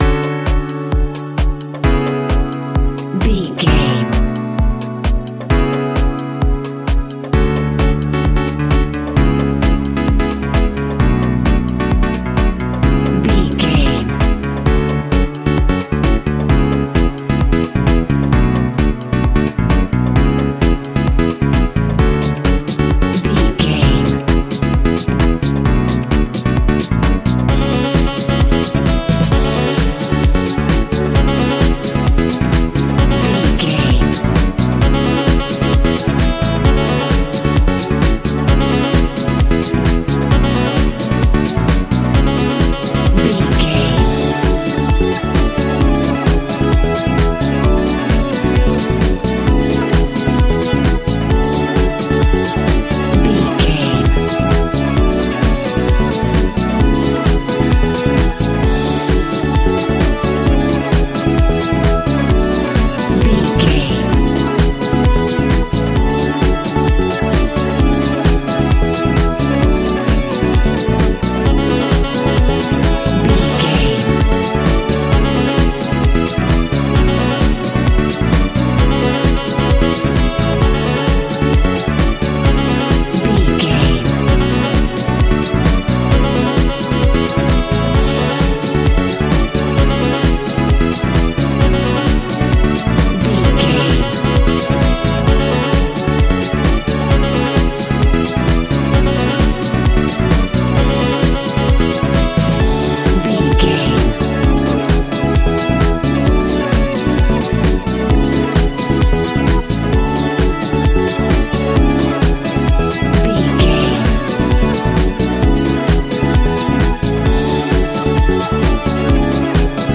Aeolian/Minor
F#
groovy
uplifting
driving
energetic
bass guitar
electric piano
synthesiser
drums
saxophone
strings
funky house
nu disco
upbeat
funky guitar
clavinet